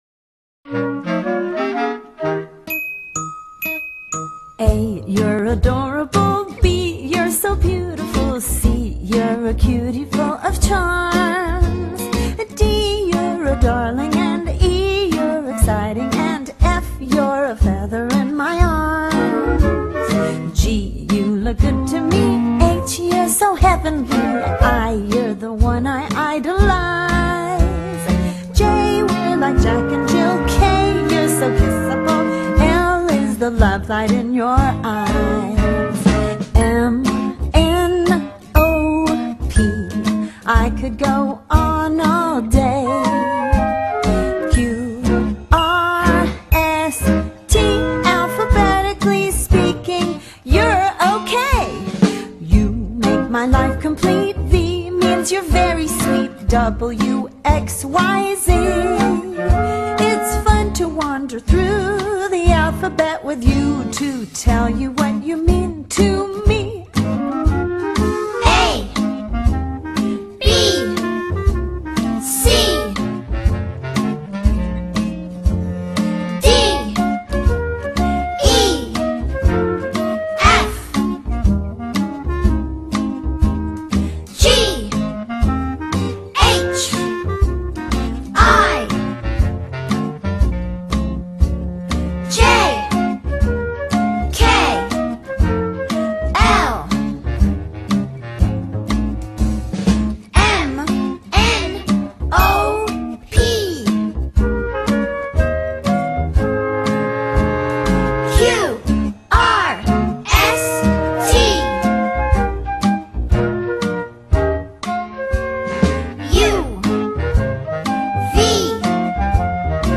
Children Song Music By